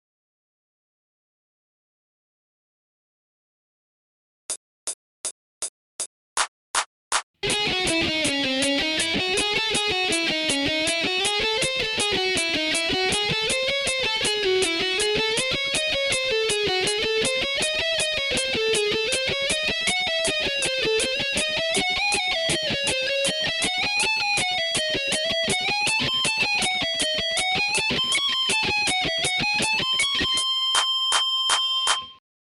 training phrase 8　ハーモニックマイナースケール〜上昇フレーズ〜
training phrase 7の逆、つまり上昇フレーズです。